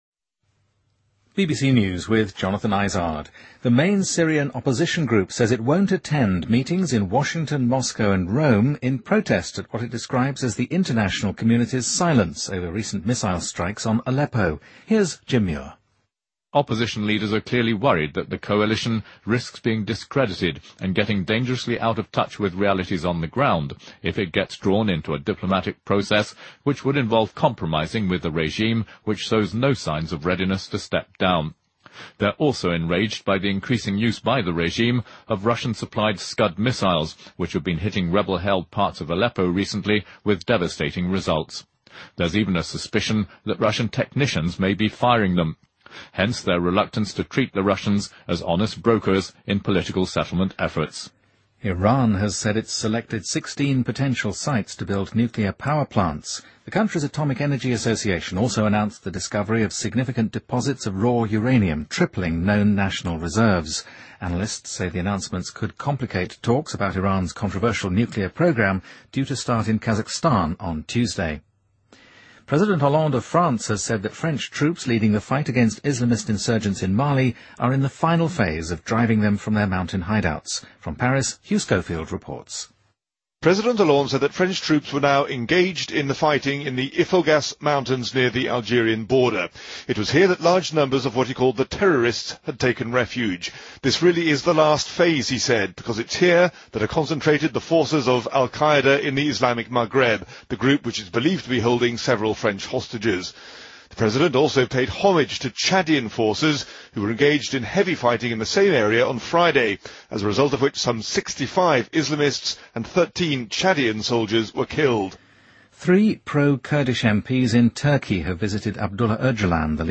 BBC news,伊朗称挑选了16个可用来建造核电厂的地点